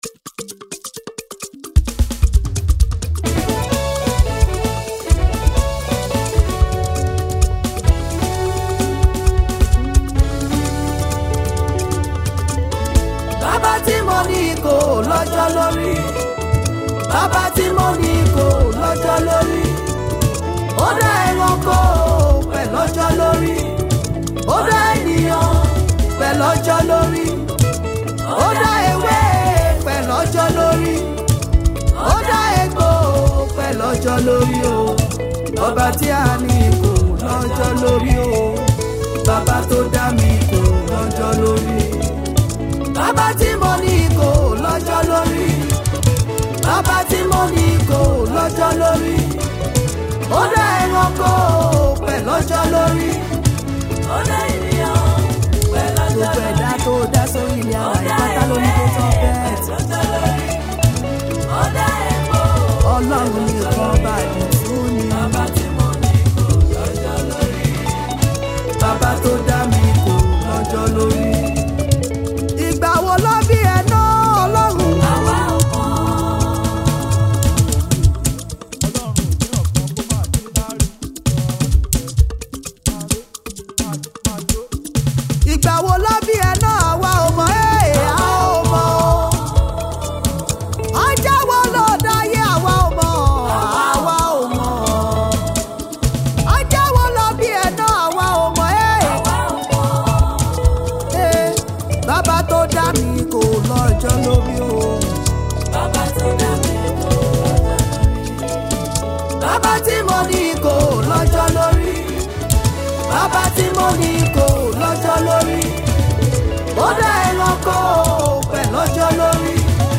powerful and spirit-filled gospel anthem
rich vocal delivery, and a deeply anointed atmosphere